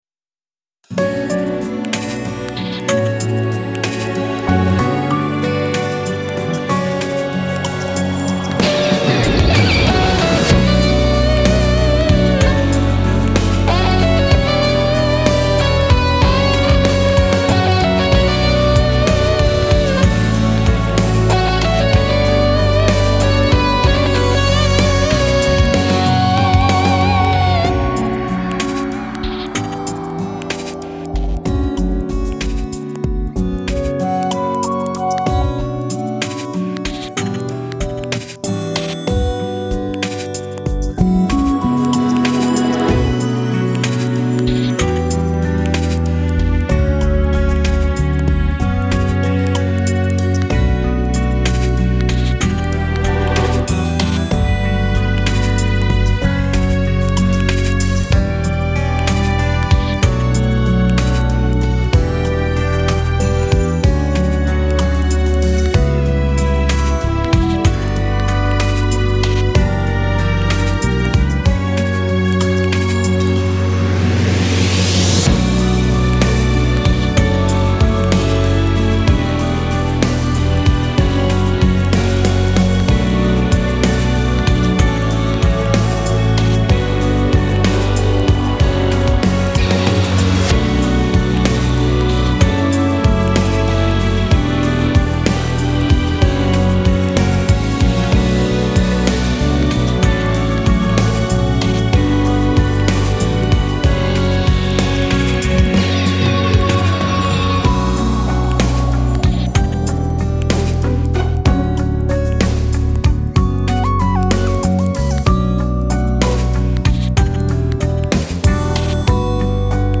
Признание в любви (Pop-Rock)
Послушайте сведение пожалуйста, друг мой как то давно аранжировал эту песню, взял мультитрек у него попрактиковаться в сведении) вся аранжировка выполнена софтово, планируется запись живых гитар и голос скоро будем записывать. На мастере Invisible с настройками +3db только.